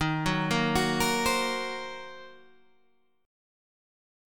Ebm6 chord